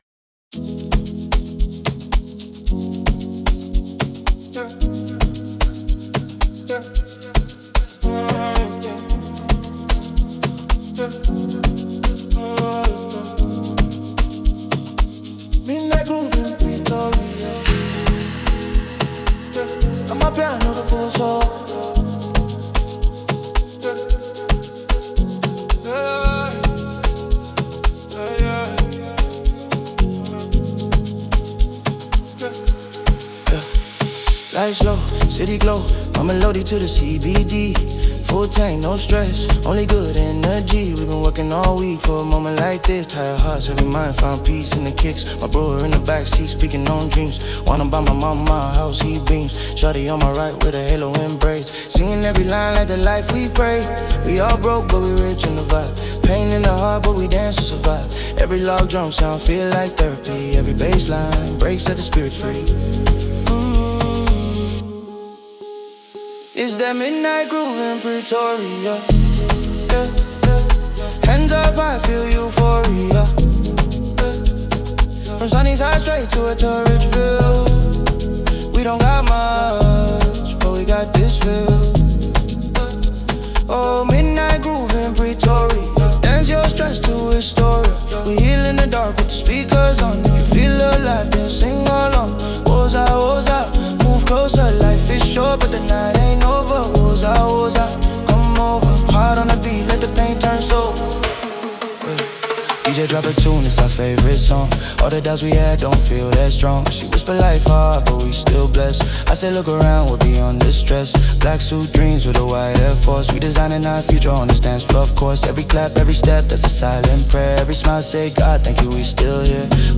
Amapiano 2025 Non-Explicit